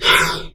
Heavy Breaths
BREATH2M.wav